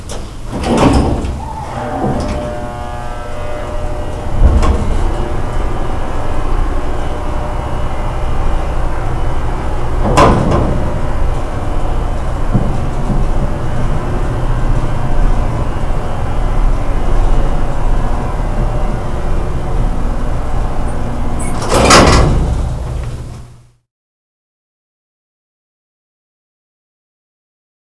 Elevator Audio
Gated service elevators are noisy.
This audio file was recorded as the service elevator traveled between two floors. The file captured the elevator sounds in real time and was not manipulated in any way.
elevator music.aif